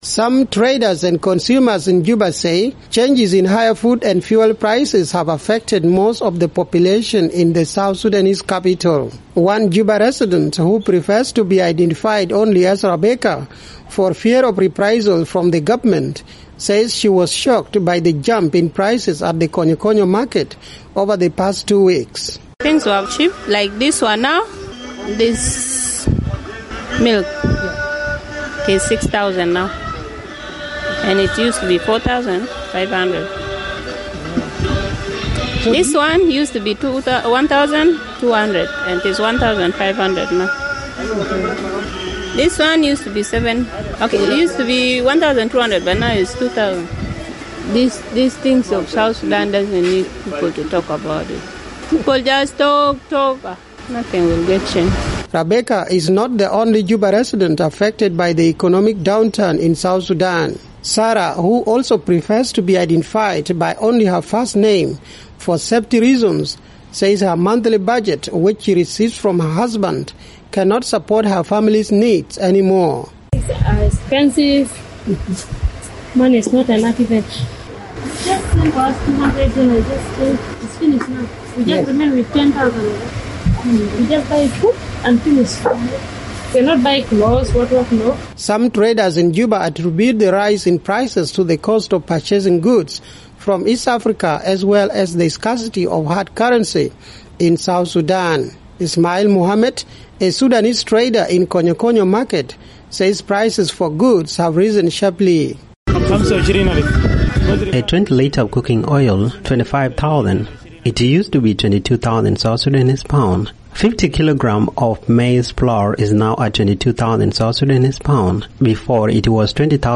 Some traders and consumers in Juba say changes in higher food and fuel prices have affected most of the population in the South Sudanese capital.